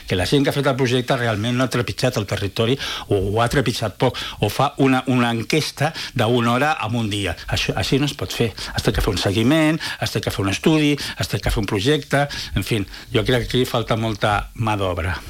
Aquest dilluns, a l’entrevista del matinal de RCT